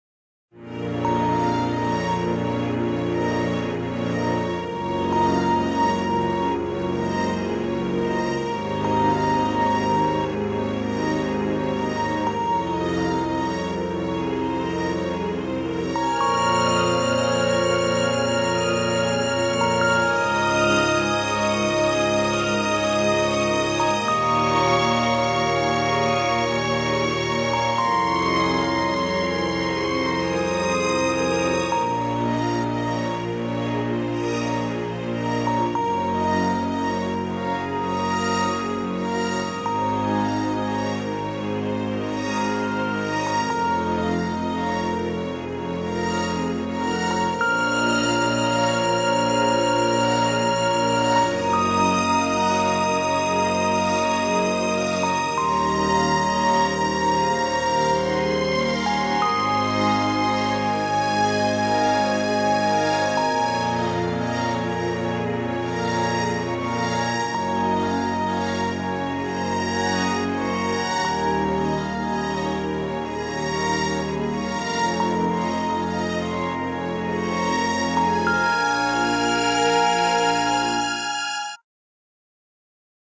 Another Strings Tune, Rpg Adventure